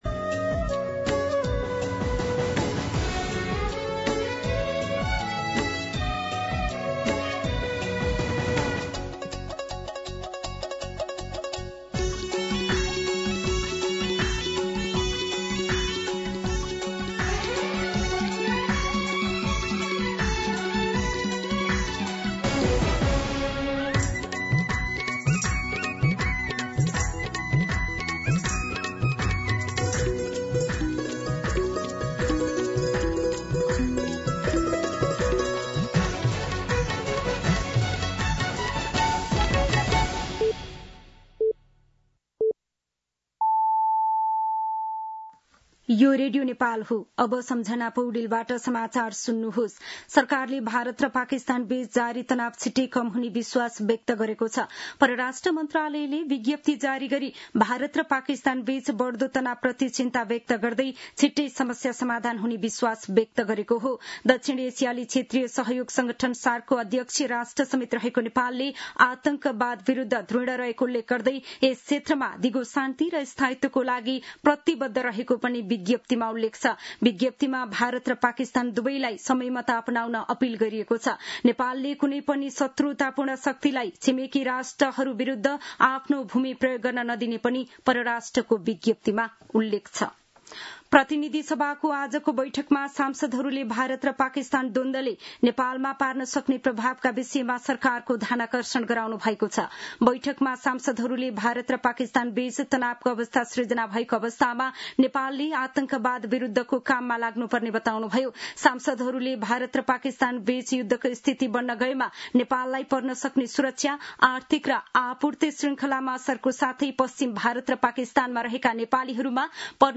An online outlet of Nepal's national radio broadcaster
दिउँसो १ बजेको नेपाली समाचार : २६ वैशाख , २०८२